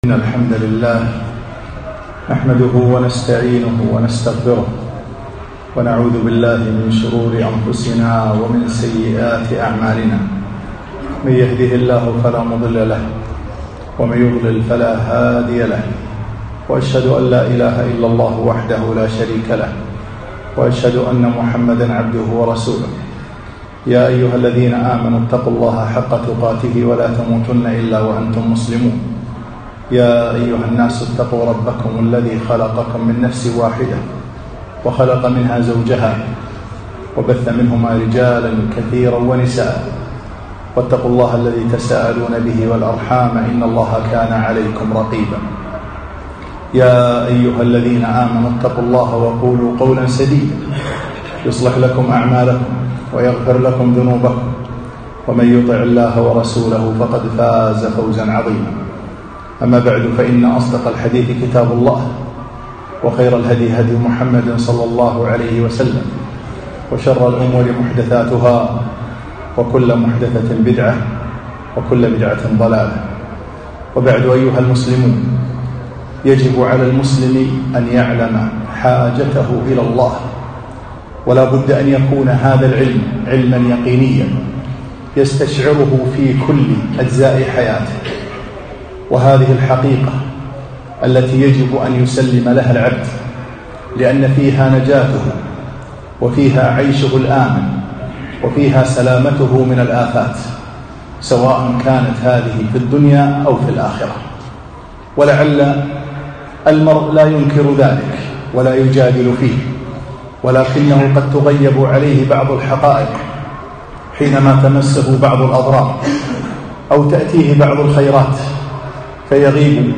خطبة - حاجتنا إلى الله